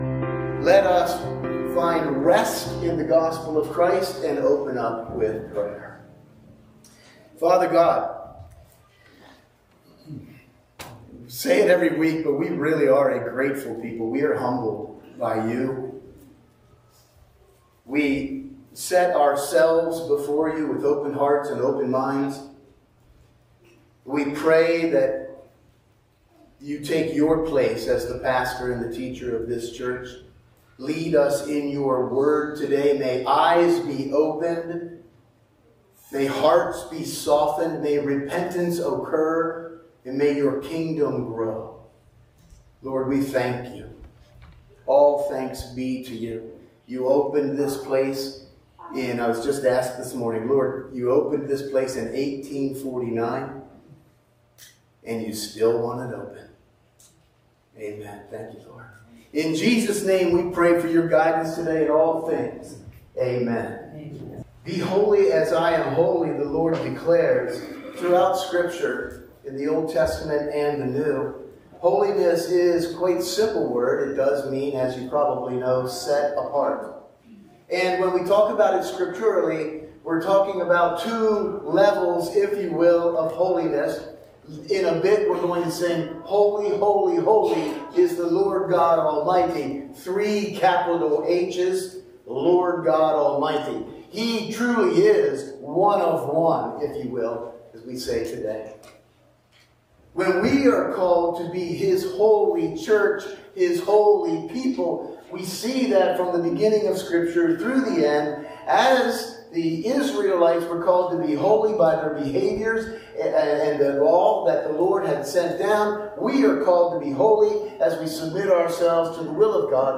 Sunday Morning Service – February 23, 2025 – Churchtown Church of God
Join us for the Sunday morning service at the Churchtown Church of God.